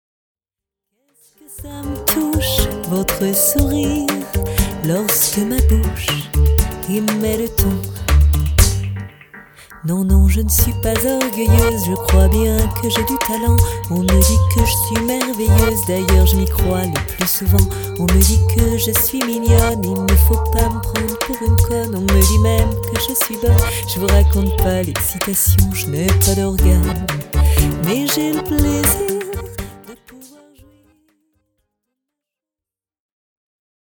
version tango electro